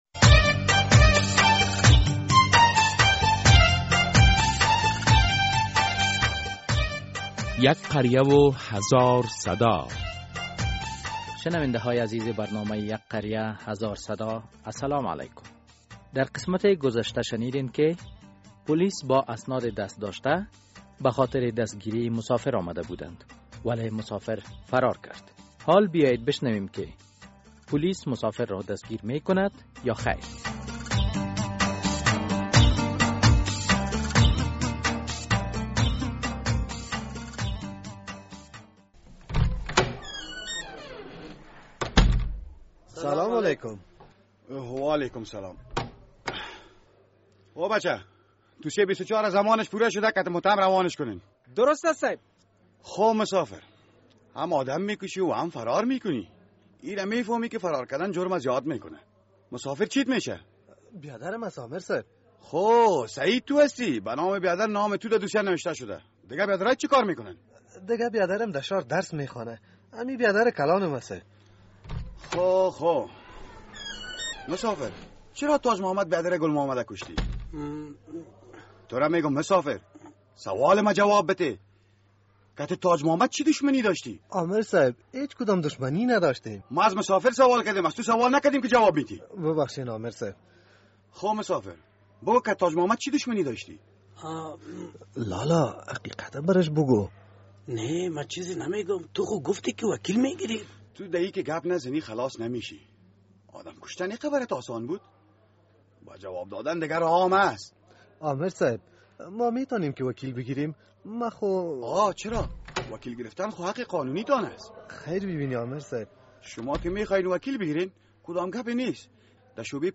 یو کلي او زر غږونه ډرامه هره اوونۍ د دوشنبې په ورځ څلور نیمې بجې له ازادي راډیو خپریږي.